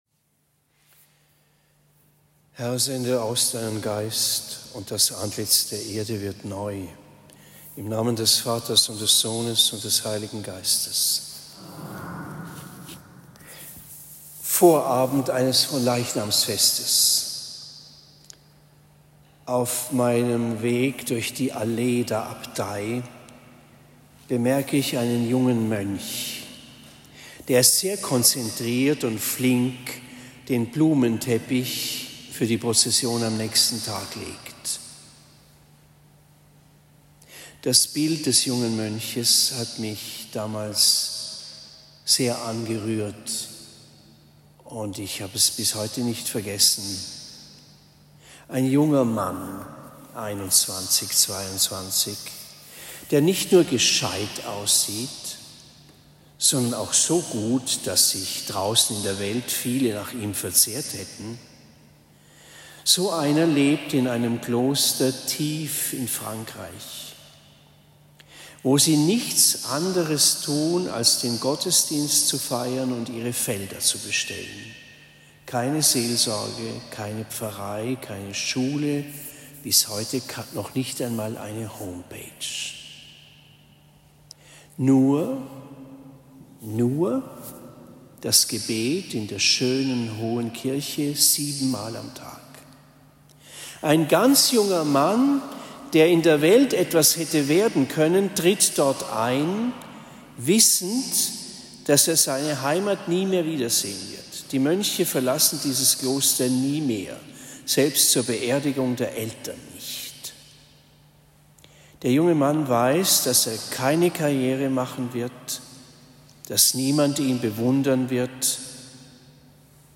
Predigt am 21. Juni 2024 in Oberndorf Herz-Mariä